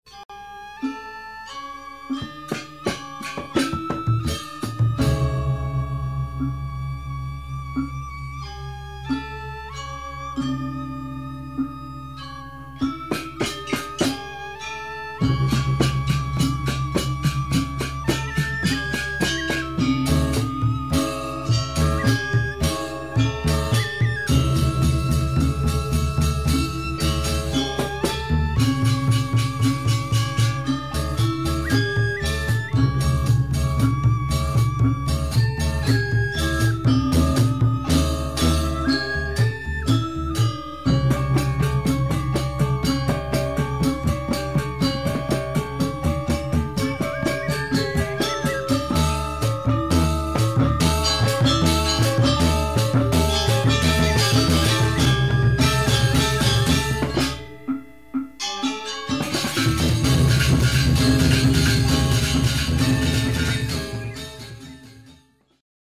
Gamelanas.mp3